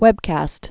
(wĕbkăst)